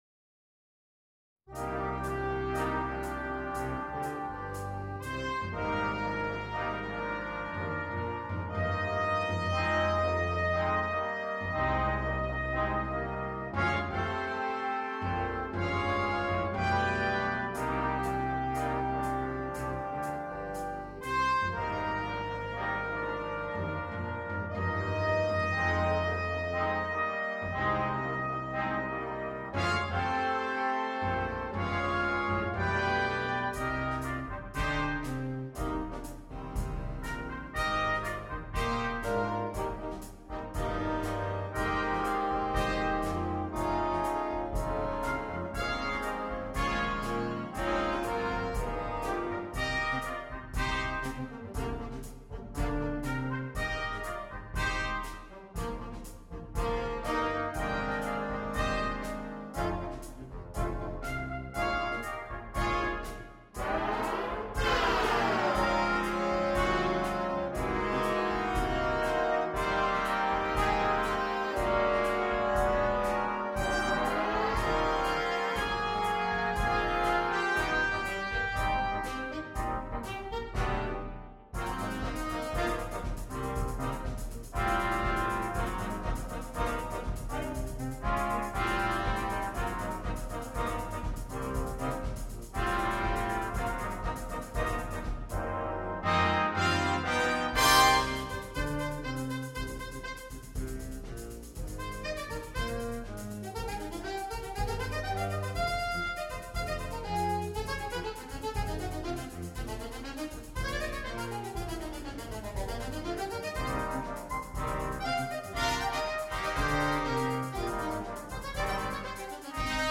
на биг-бэнд.